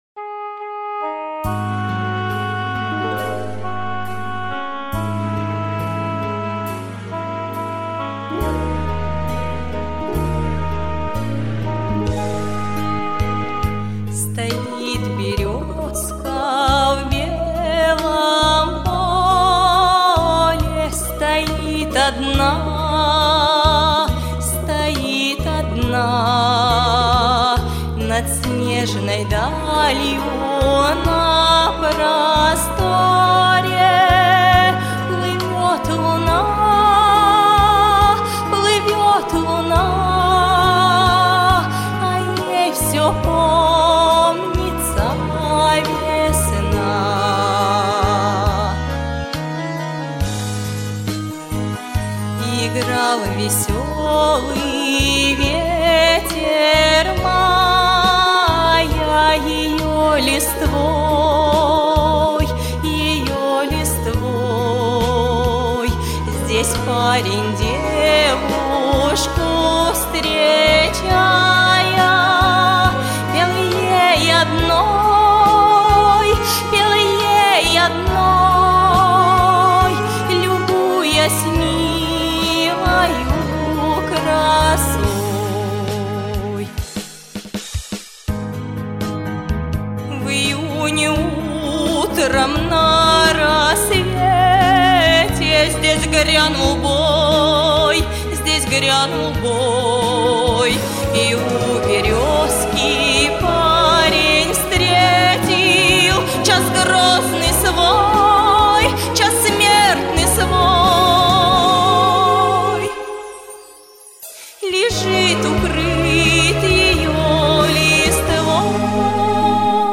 Оказывается, поют народные певицы до сих пор: